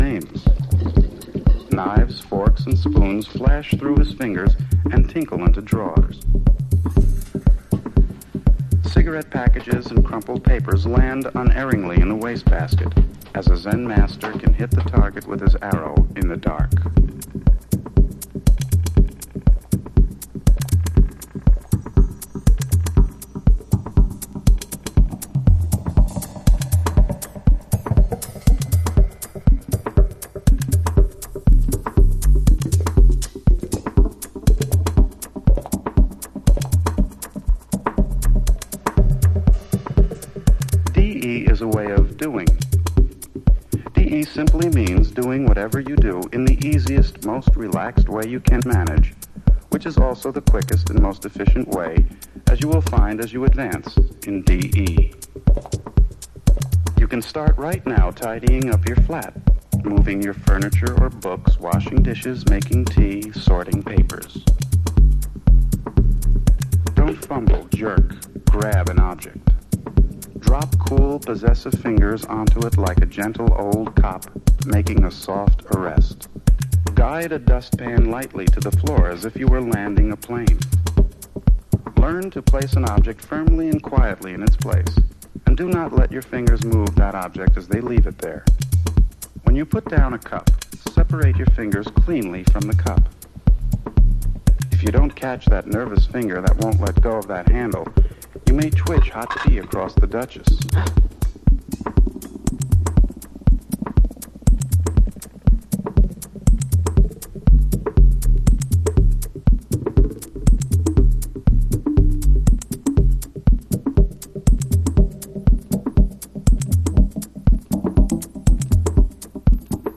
House / Techno
SIDE-Bのドラムのタイム感もドツボ。